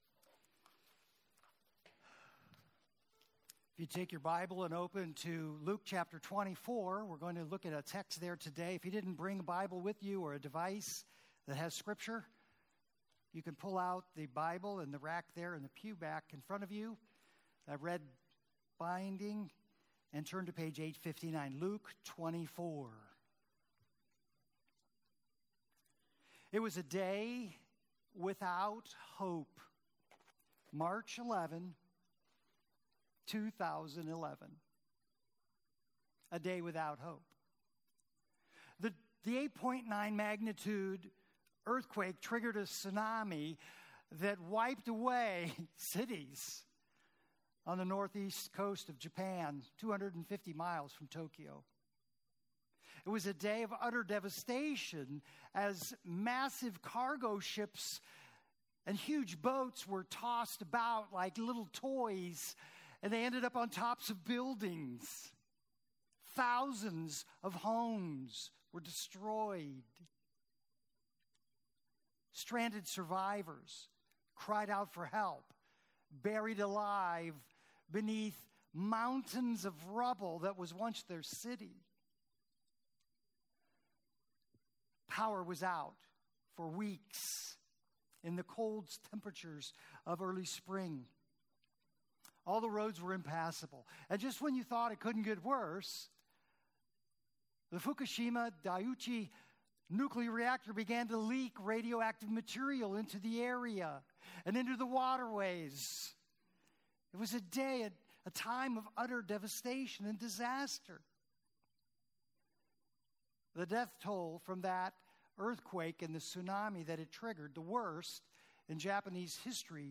Home New Here Events About Our Beliefs Next Steps Meet our Team Ministries Bless App Kids Youth Women Men Senior Adults Prayer Give Contact Previous Sermons EASTER – He’s Alive – And Closer than You Think!